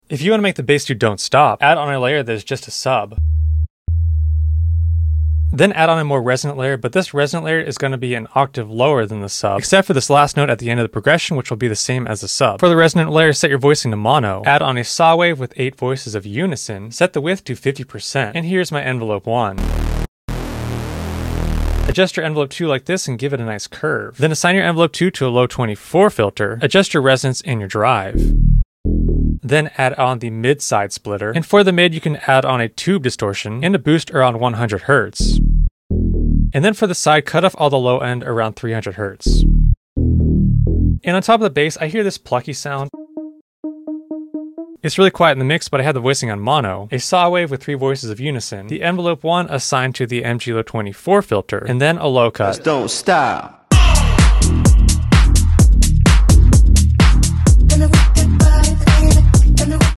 Bass Synth in Serum 2
Tech House, Bass